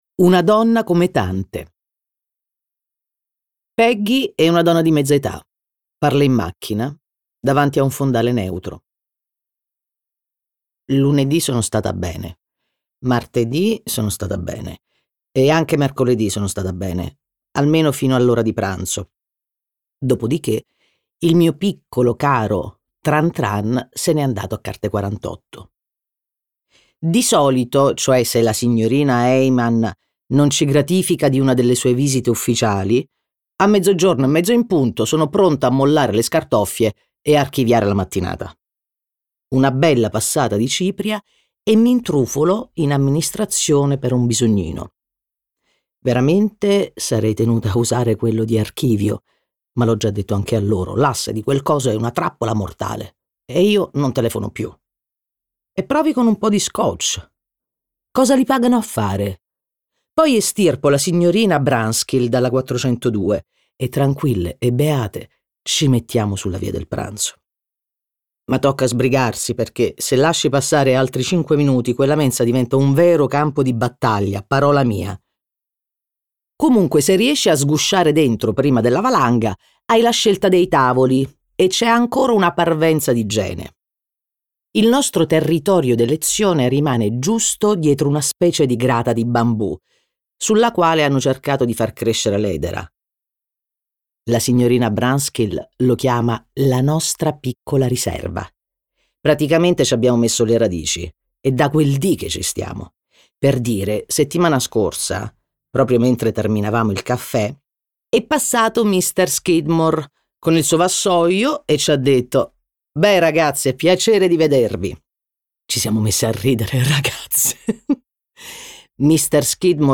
letto da Vanessa Scalera, Corrado Guzzanti
Versione audiolibro integrale